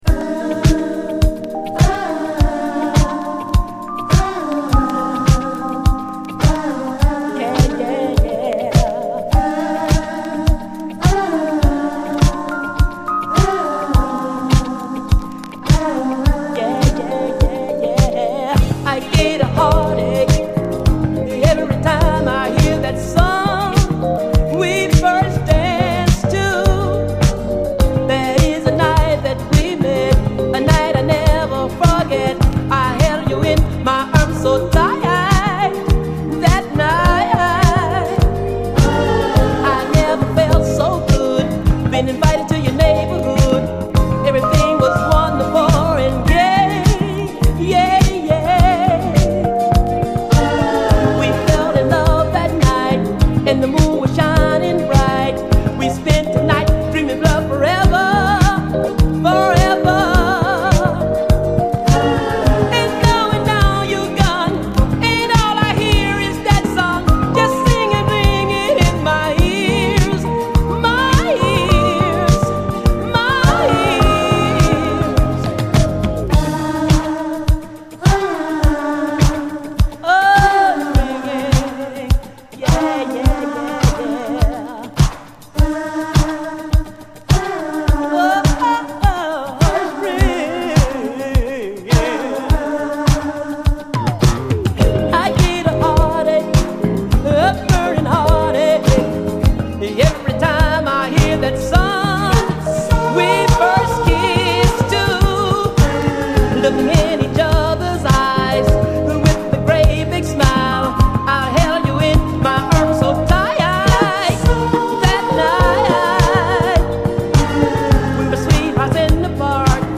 SOUL, 70's～ SOUL, 7INCH
フレンチ・メロウ・モダン・ソウル45！
しっとりとしたエレピとコーラスが包み込む、ナイス・メロウ・ソウル！